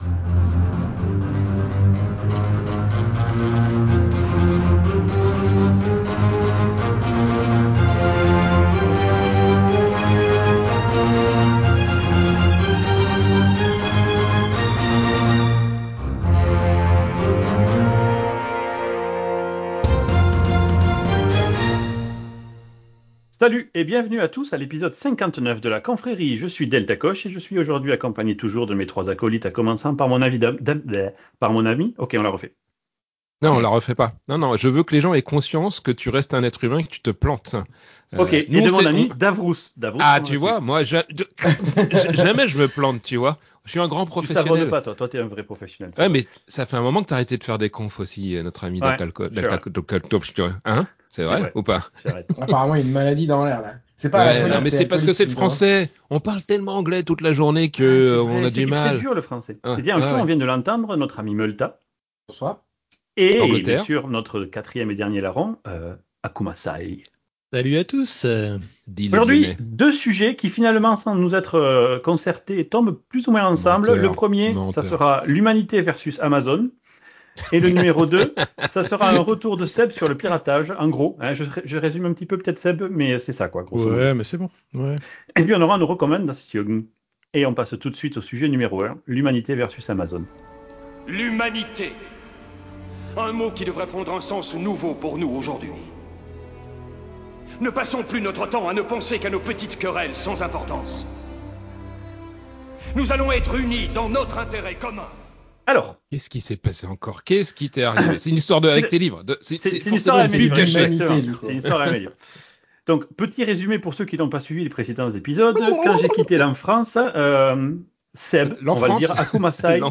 La Confrérie est un podcast de discussion entre potes sur les sujets qui nous intéressent (En general cela reste cependant tres geek)